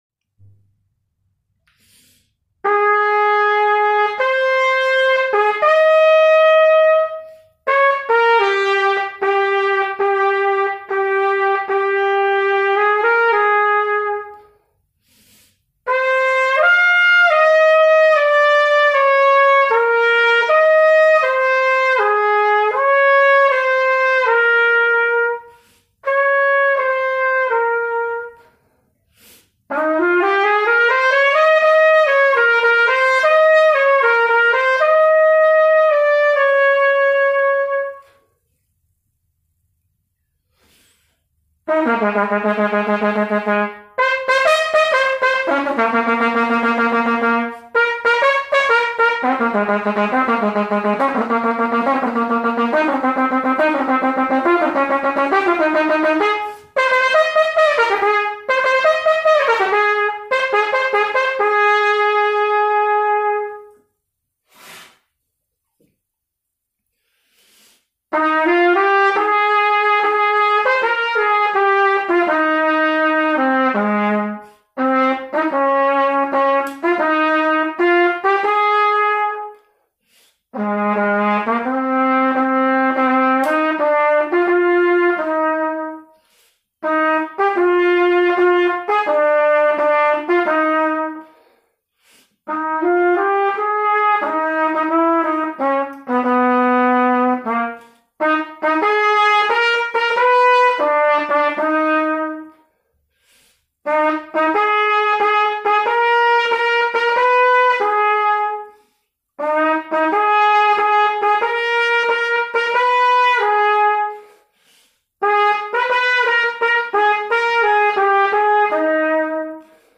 На трубе